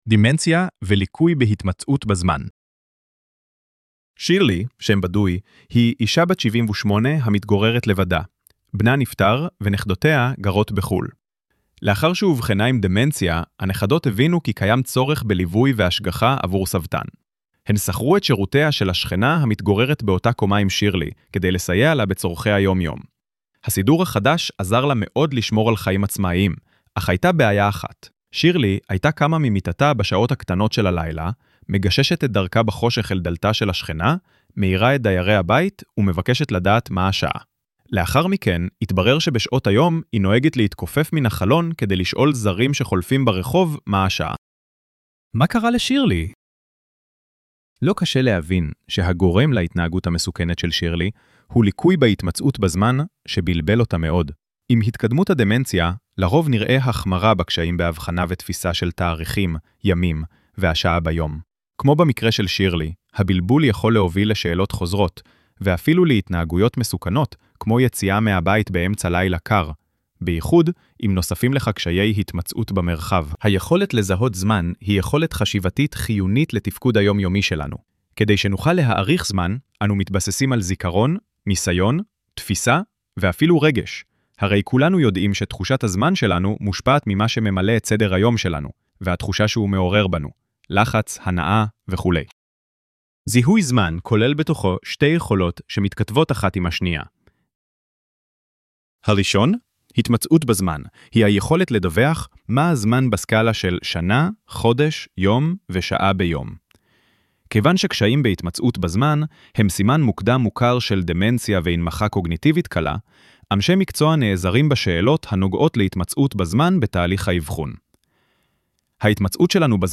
ElevenLabs_דמנציה_והתמצאות_בזמן-1.mp3